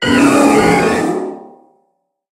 Cri de Méga-Empiflor dans Pokémon HOME.
Cri_0071_Méga_HOME.ogg